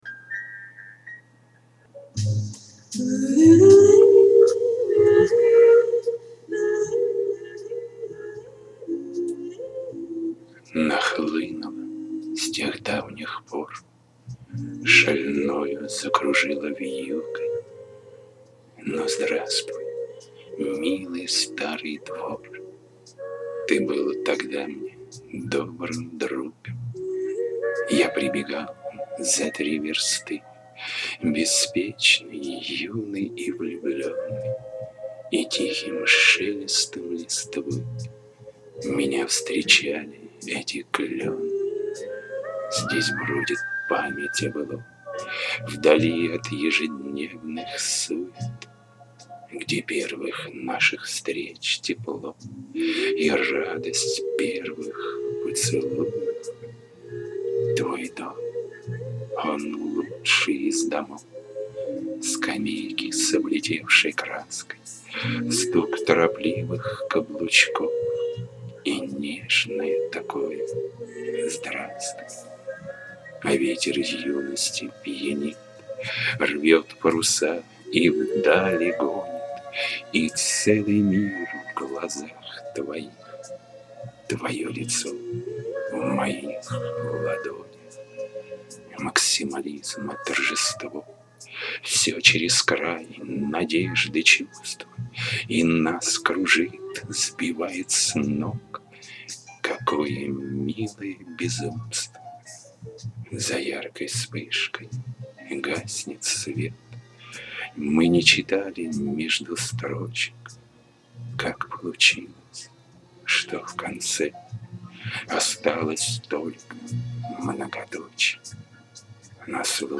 Декламация приятная.